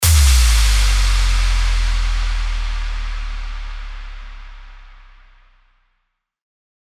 FX-884-IMPACT
FX-884-IMPACT.mp3